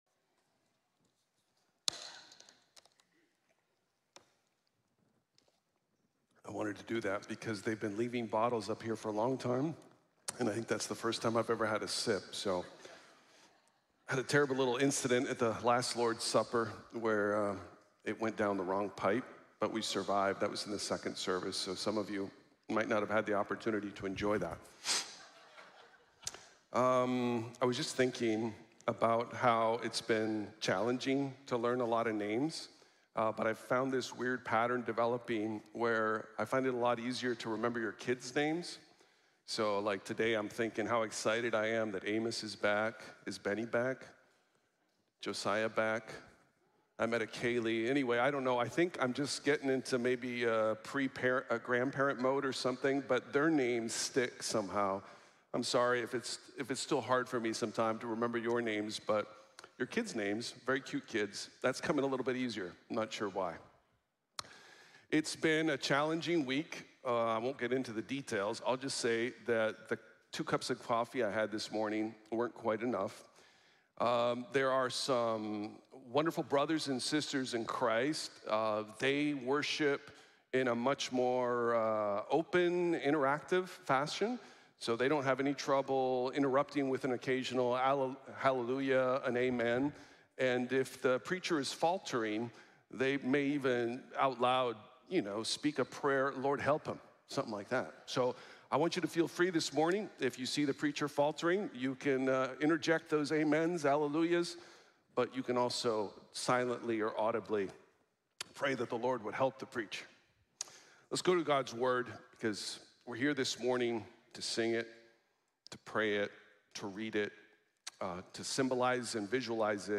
Salvation Comes from the Lord | Sermon | Grace Bible Church